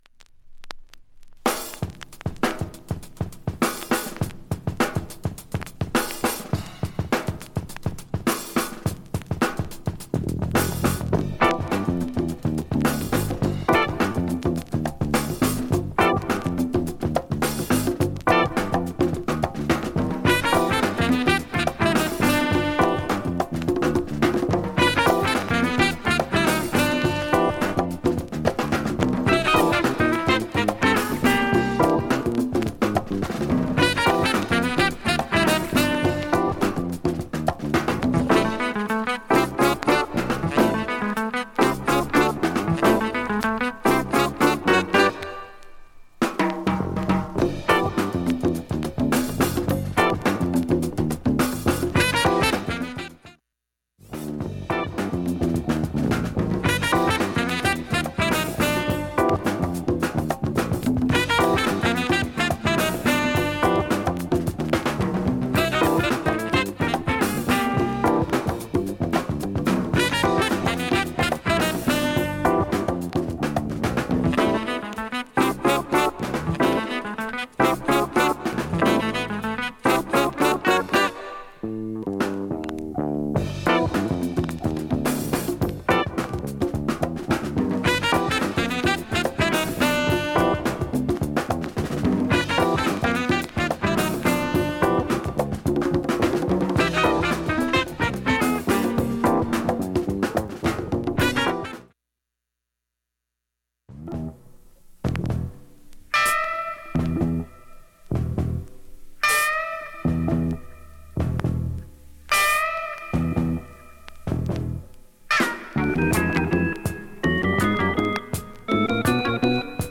SOUL、FUNK、JAZZのオリジナルアナログ盤専門店
瑕疵部分 1,A-1始め５０秒にかすかなプツが 数回数か所出ていますがかすかです。